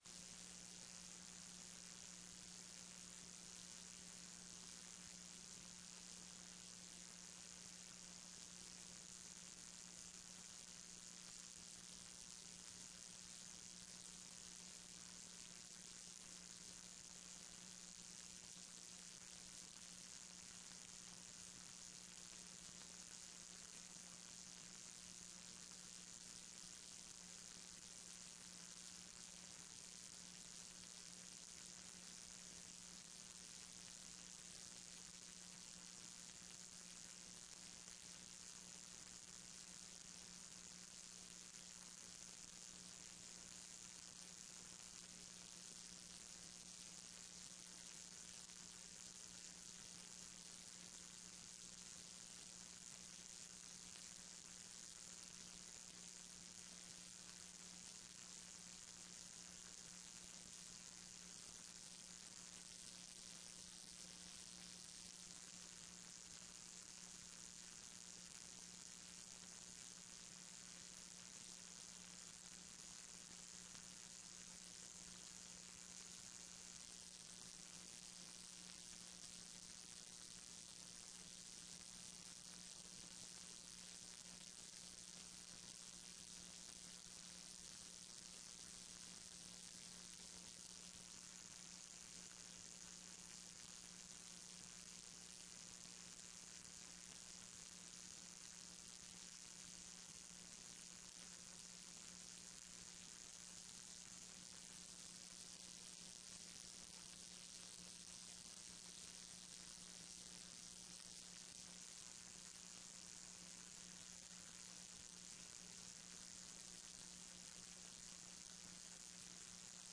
TRE-ES sessão do dia 03 09 14